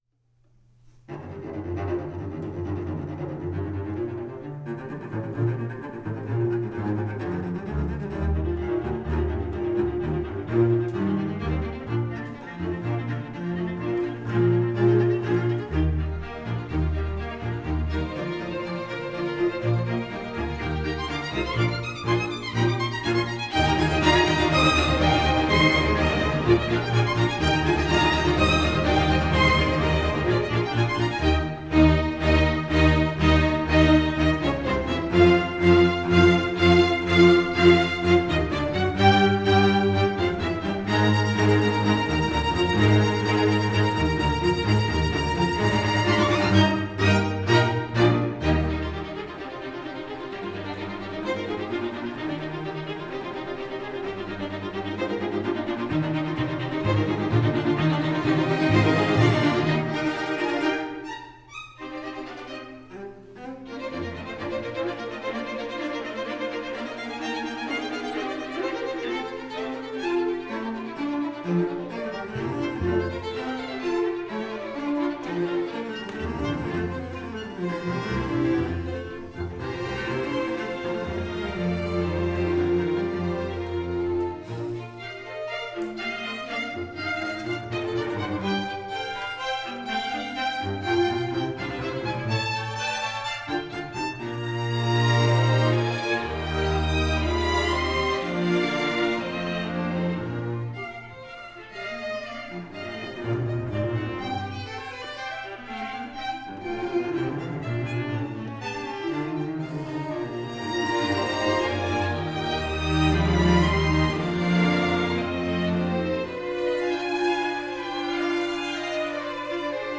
String Orchestra (D)